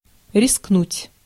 Ääntäminen
IPA: [a.fʁɔ̃.te]